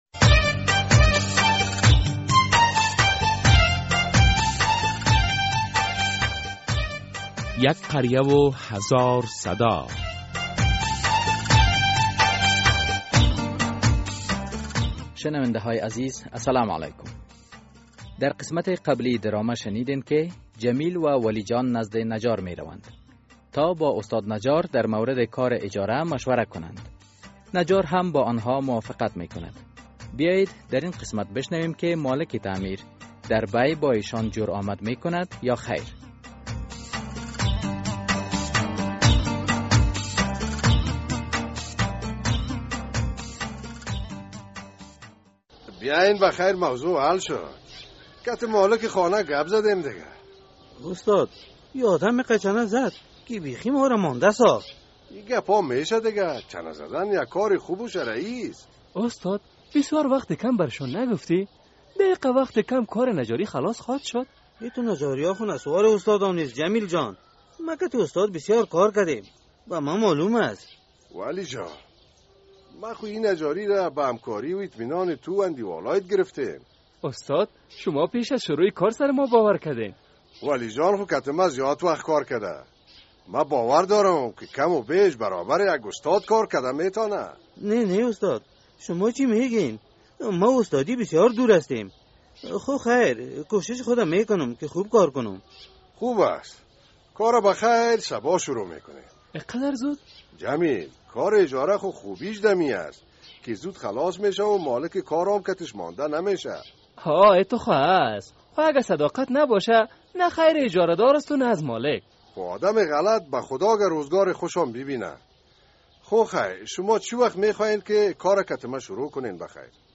در این درامه که موضوعات مختلف مدنی، دینی، اخلاقی، اجتماعی و حقوقی بیان می گردد هر هفته به روز های دوشنبه ساعت 3:30 عصر از رادیو آزادی نشر می گردد.